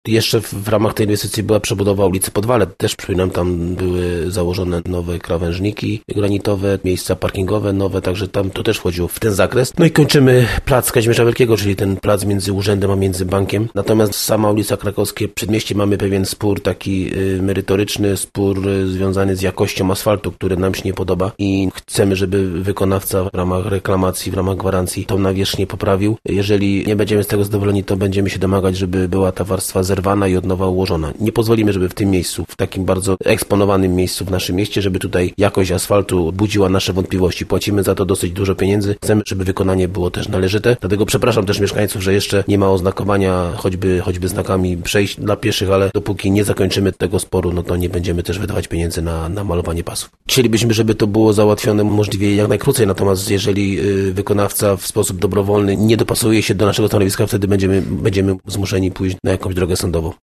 – mówił burmistrz Paweł Okrasa.